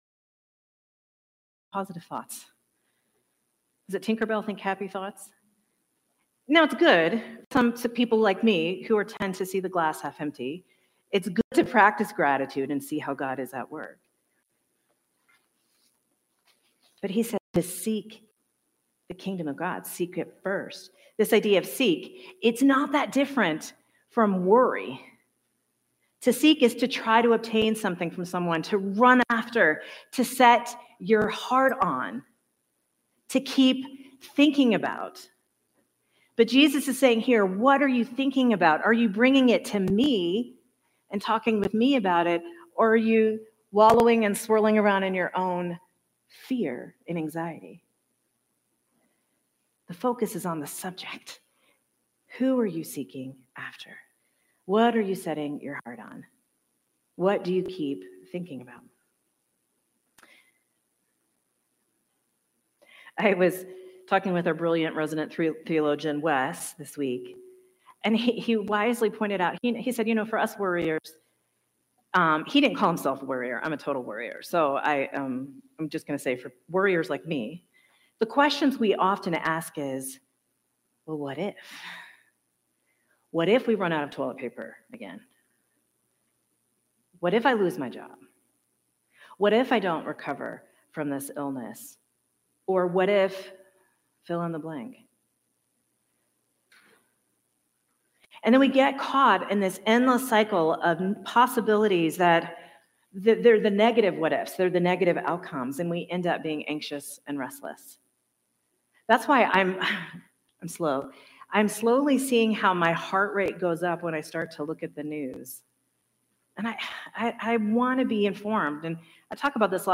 Sermon from Celebration Community Church on August 17, 2025
This video is missing the first 14 minutes of the sermon.